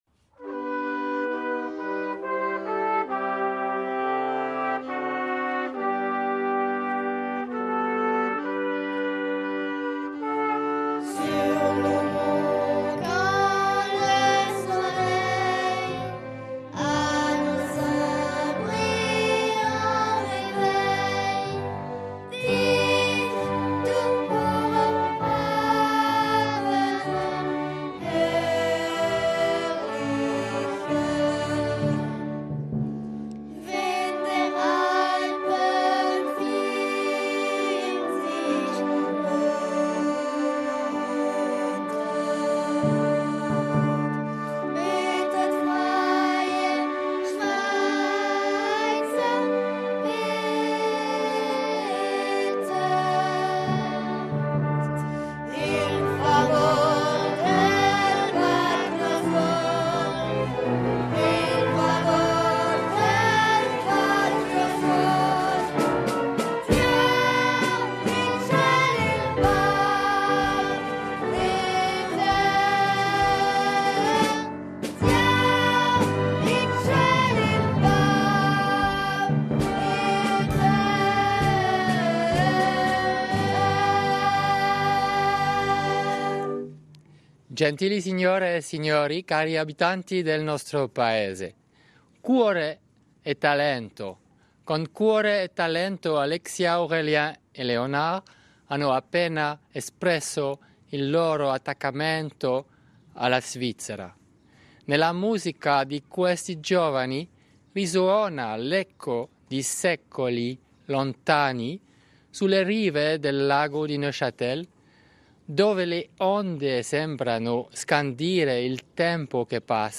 L'allocuzione del presidente della Confederazione, Didier Burkhalter